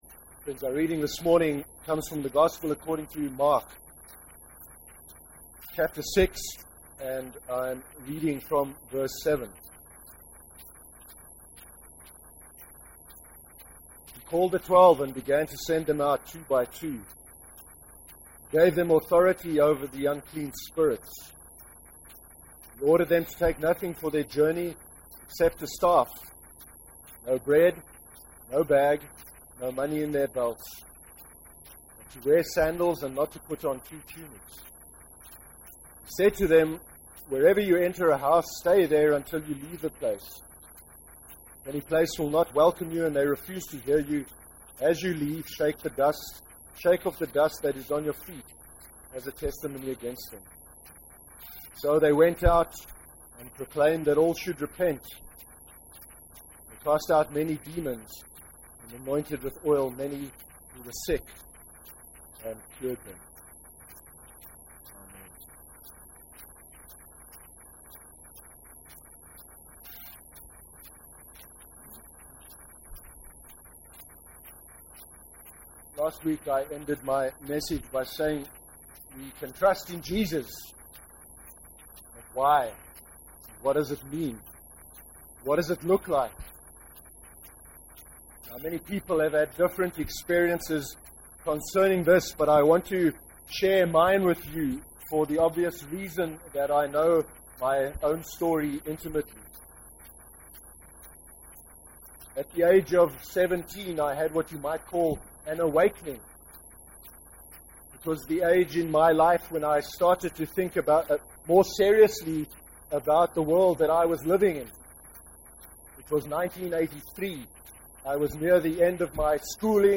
27/01/13 sermon – Jesus sends out the twelve (Mark 6:7-12)